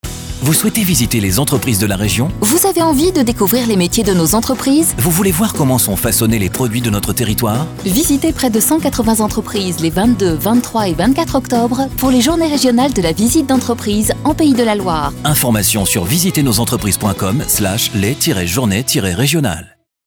Spot-radio-JRVE-2020-VNE.mp3